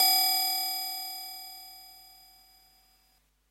CINE Sounds " Triangle Ring Soft
描述：三角形的软环
标签： 仪器 三角形 击中
声道立体声